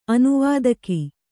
♪ anuvādaki